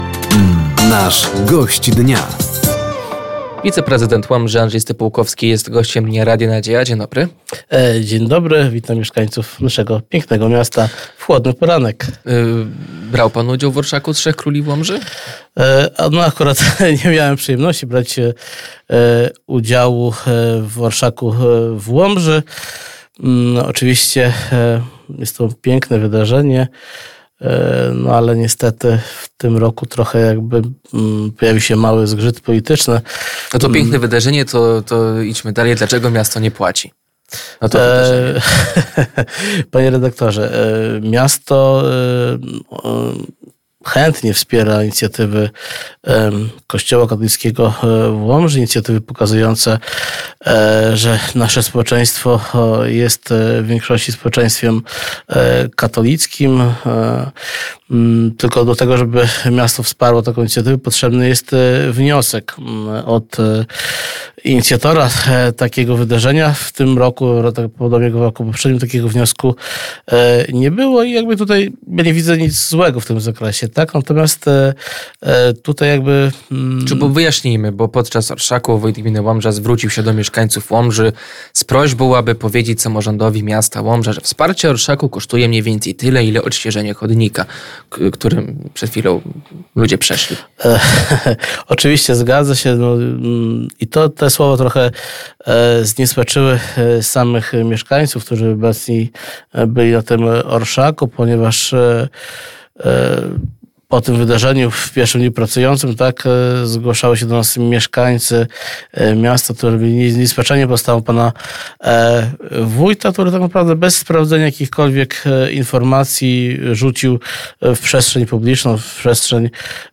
Gościem Dnia Radia Nadzieja był wiceprezydent Łomży Andrzej Stypułkowski. Tematem rozmowy było między innymi wsparcie finansowe Orszaku Trzech Króli przez miasto, baza PKS w Łomży oraz plany na ten rok.